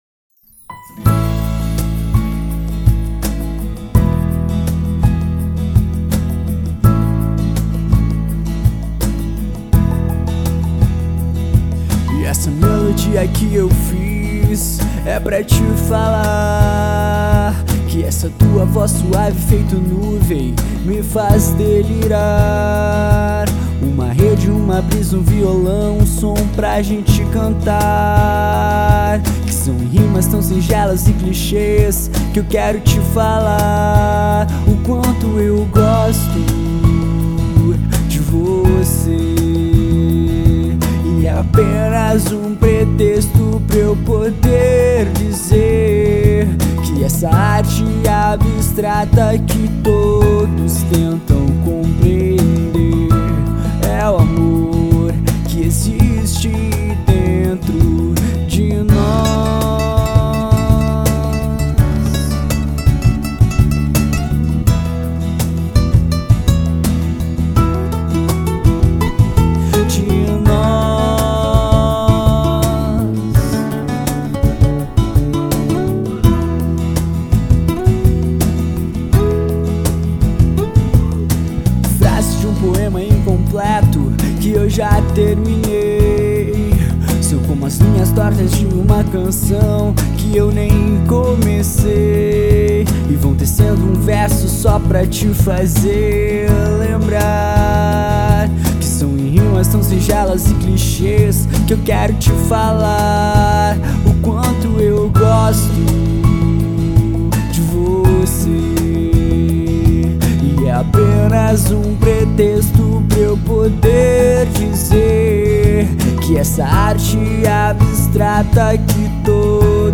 EstiloReggae